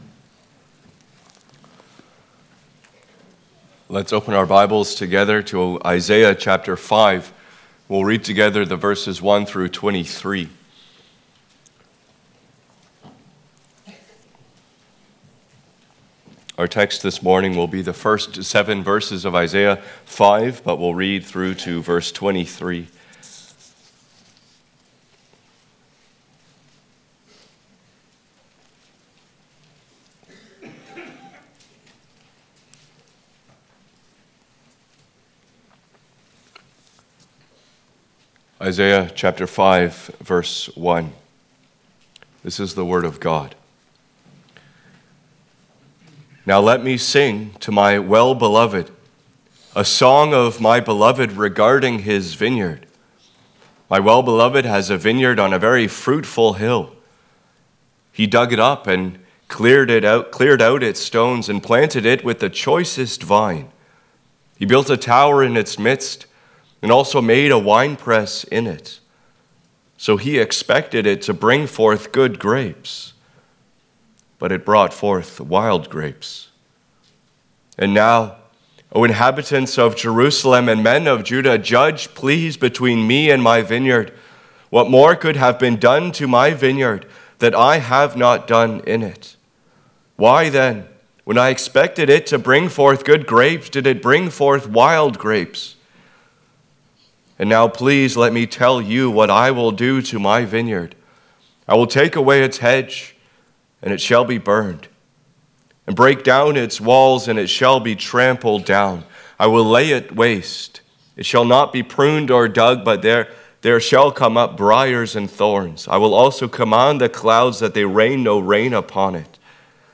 Passage: Isaiah 5:1-7, John 15:1-11 Service Type: Sunday Morning